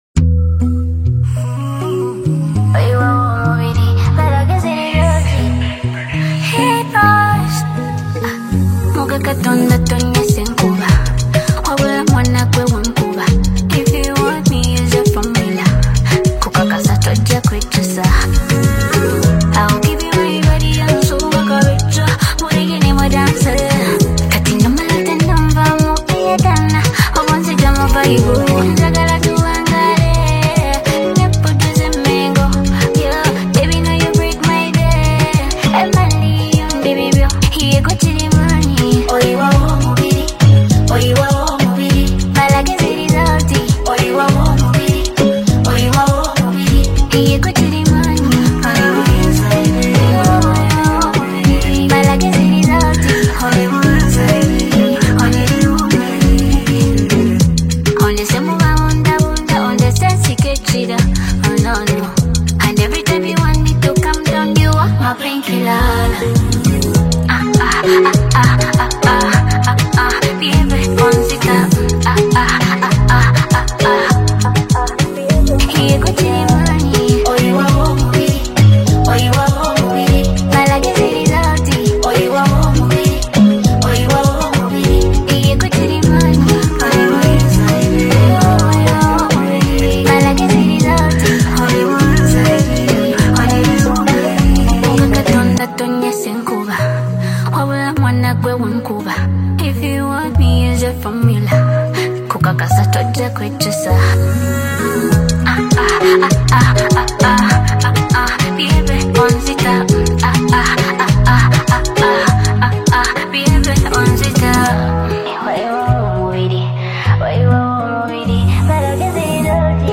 atmospheric soundscape
powerful yet delicate voice